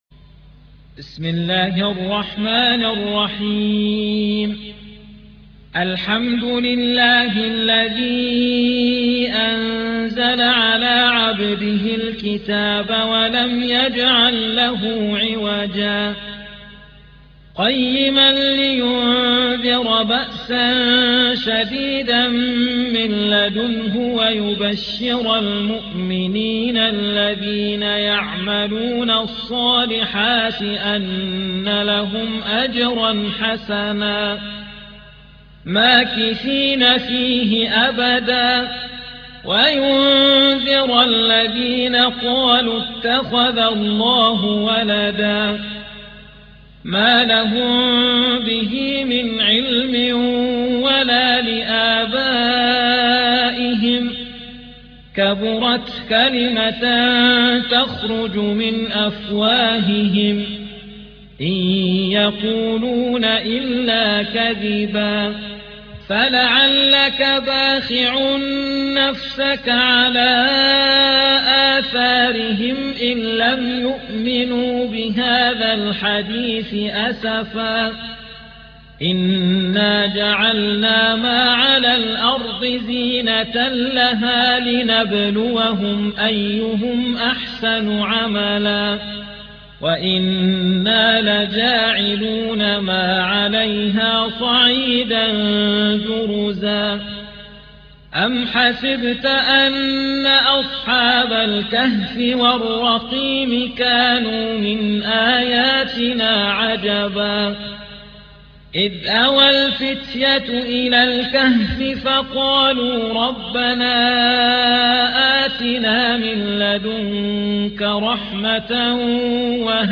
18. سورة الكهف / القارئ